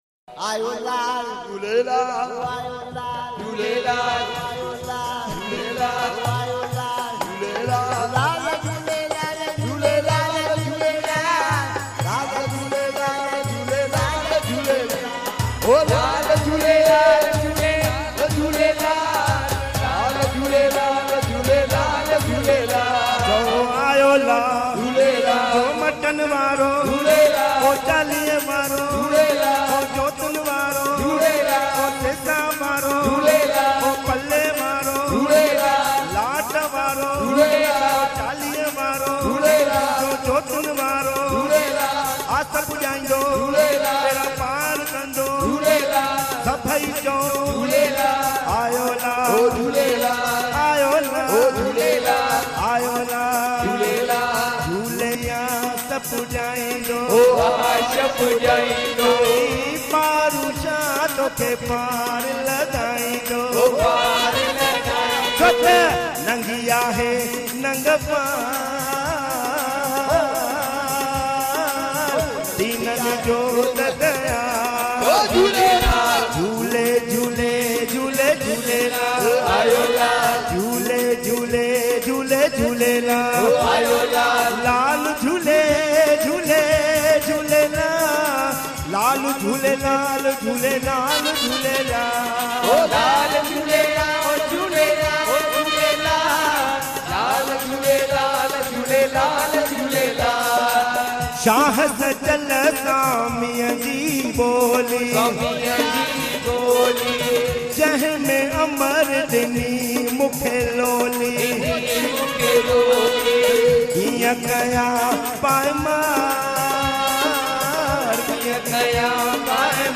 recorded at Puj Chaliha Sahib Jhulelal Mandir